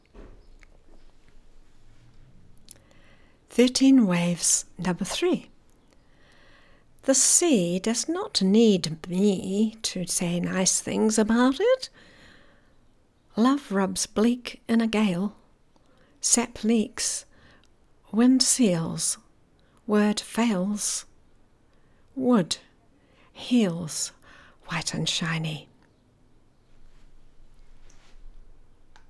But it’s not a poem, it’s a song, spoken, so it sounds kind of strange.